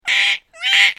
دانلود صدای پرنده 15 از ساعد نیوز با لینک مستقیم و کیفیت بالا
جلوه های صوتی
برچسب: دانلود آهنگ های افکت صوتی انسان و موجودات زنده دانلود آلبوم صدای پرندگان از افکت صوتی انسان و موجودات زنده